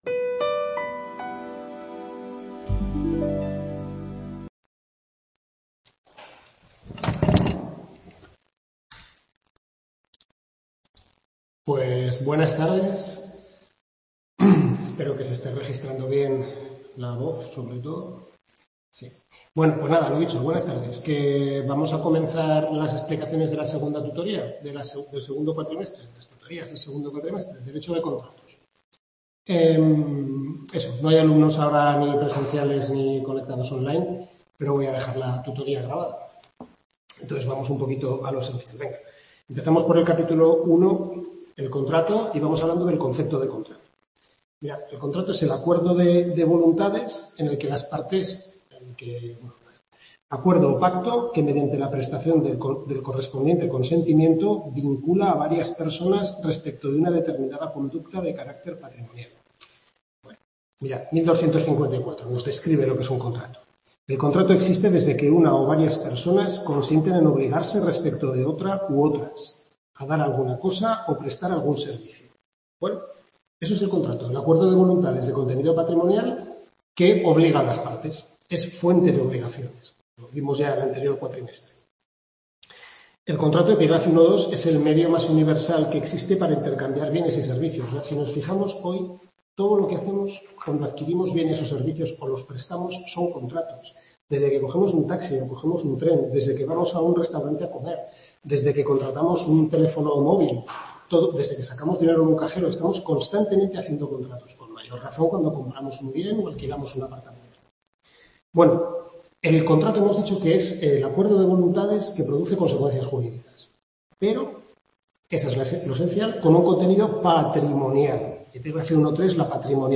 Tutoría 1/6 segundo cuatrimestre Civil II (Contratos), centro UNED-Calatayud, capítulos 1-4 del Manual del Profesor Lasarte